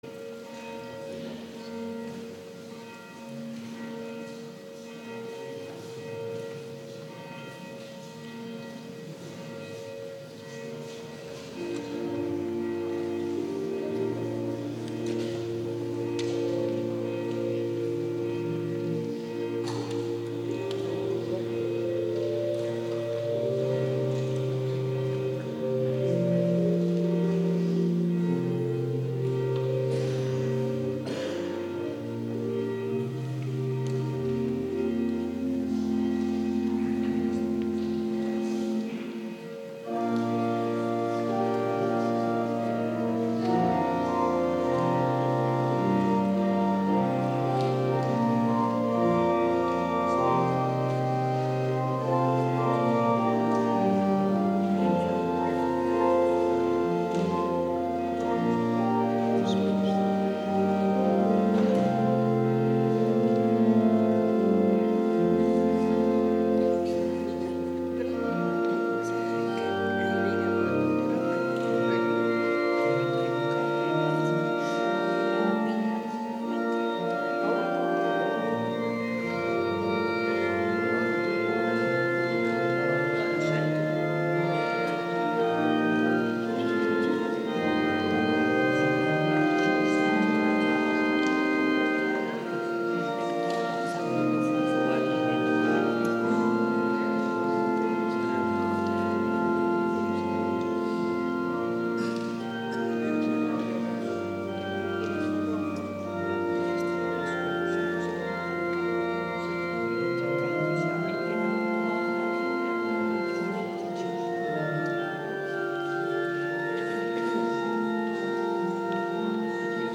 Dienst vanuit de Eligiuskerk